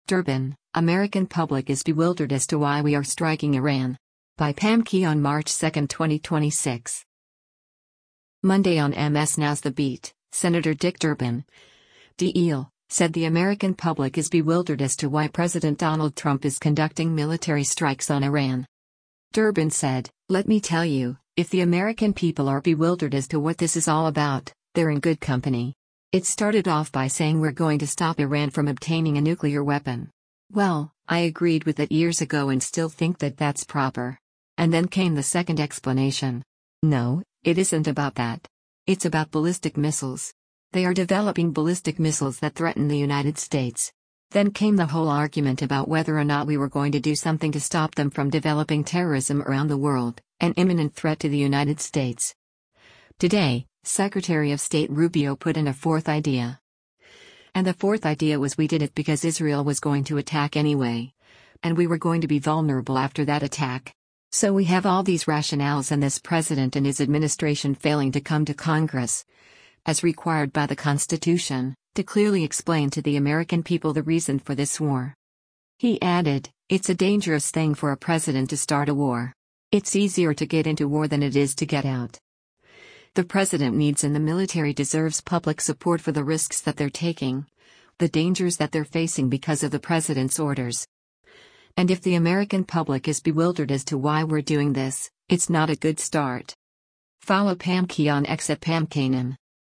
Monday on MS NOW’s “The Beat,” Sen. Dick Durbin (D-IL) said the “American public is bewildered as to why” President Donald Trump is conducting military strikes on Iran.